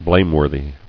[blame·wor·thy]